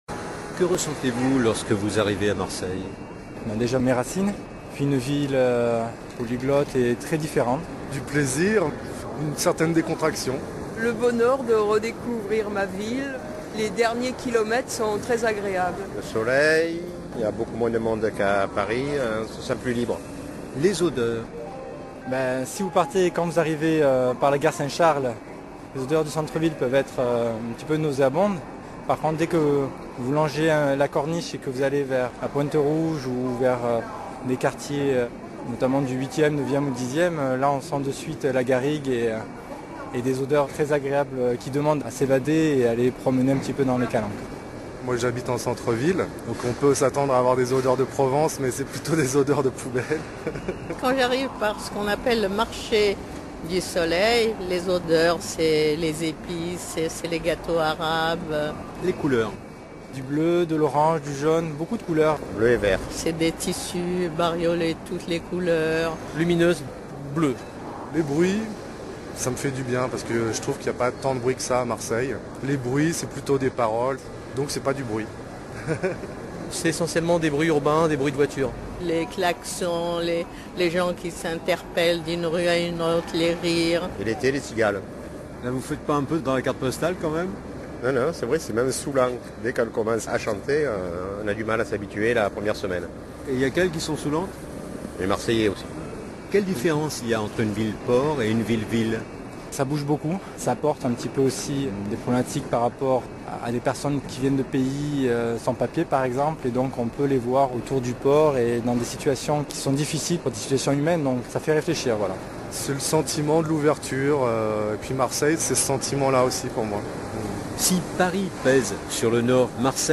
Voici comment en parlaient l’autre jour à la radio quelques Marseillais tout juste descendus du TGV.